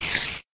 sounds / mob / spider / say2.ogg